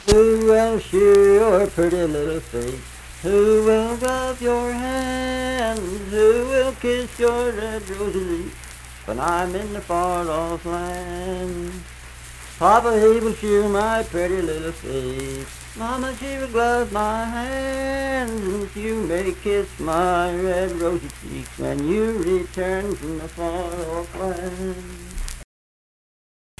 Unaccompanied vocal music
Verse-refrain 2(4w/R).
Voice (sung)